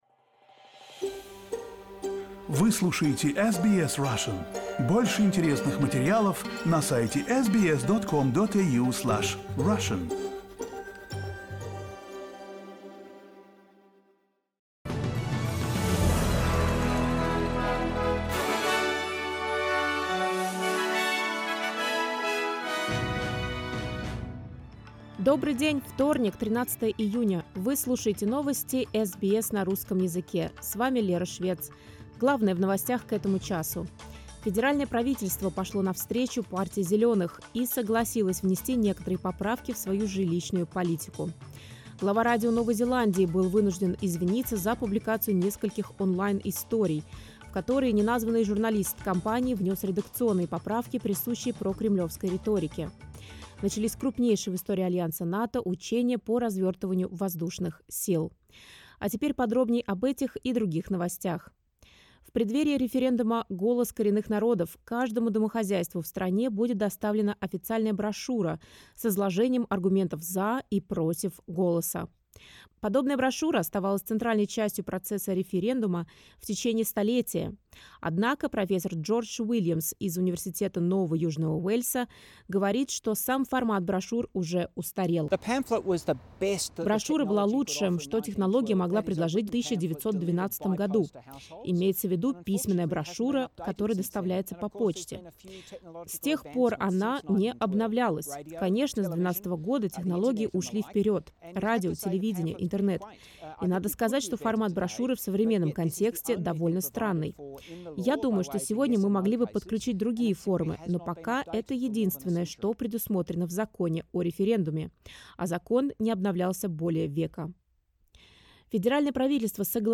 SBS news in Russian — 13.06.2023